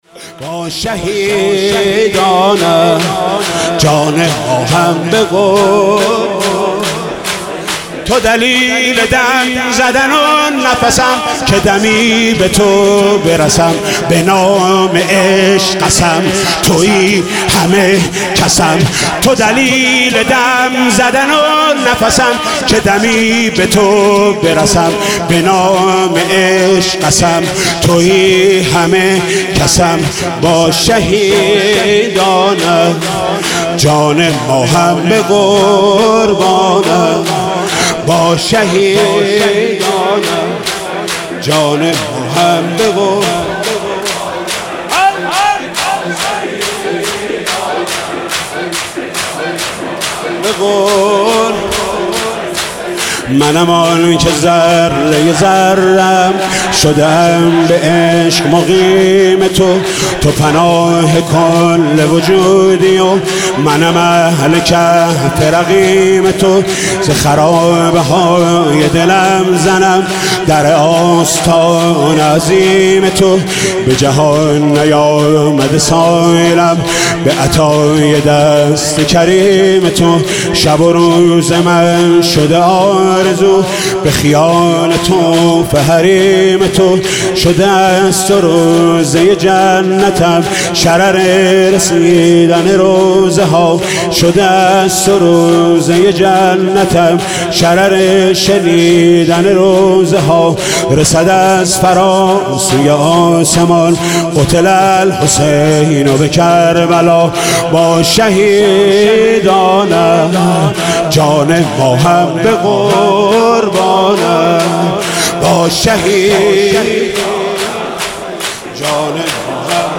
شب سوم محرم95/هیئت رایه العباس
شور/با شهیدانت